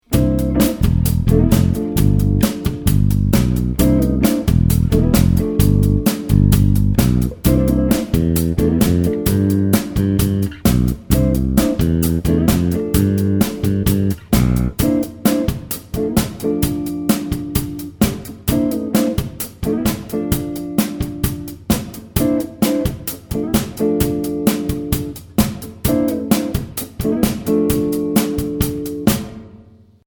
Voicing: Electric Bass